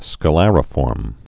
(skə-lărə-fôrm)